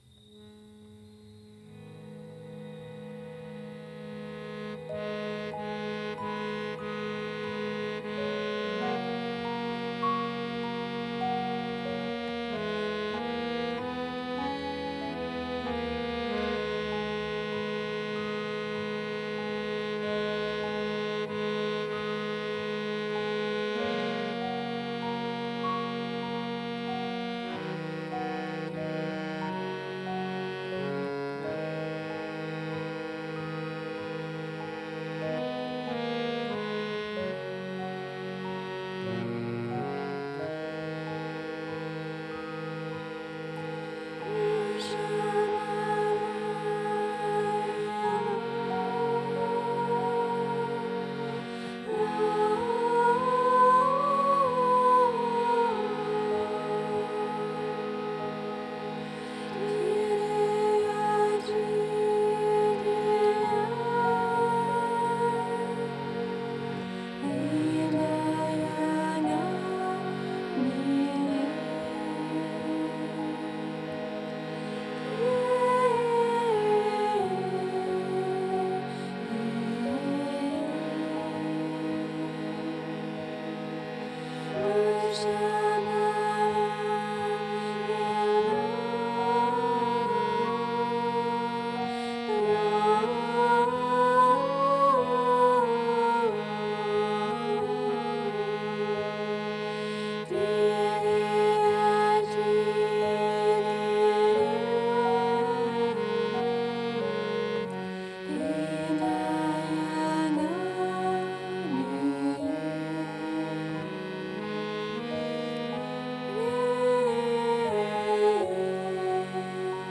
These songs are performed with love and sweetness-devotion.